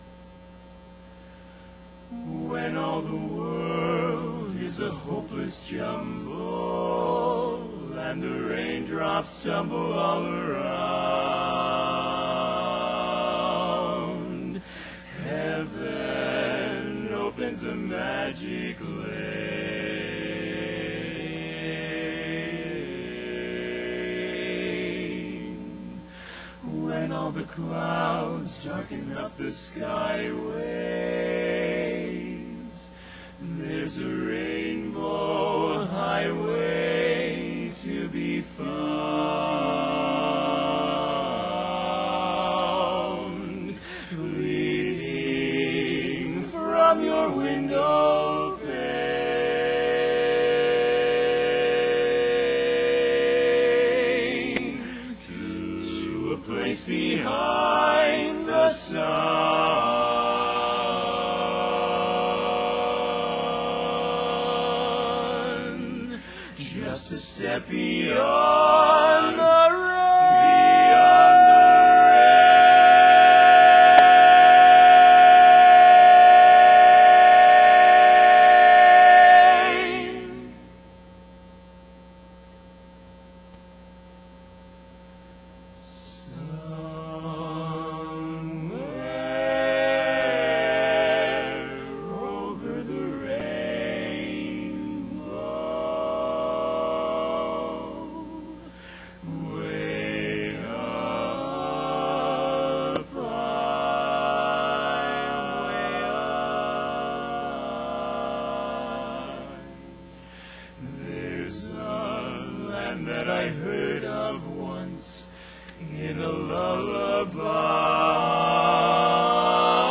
Tenor
Lead
Bari
Bass
Q was a youthful quartet of accomplished barbershop singers that burst onto the scene in 2003.
Q - Somewhere Over The Rainbow 2004 Quartet Semi-Finals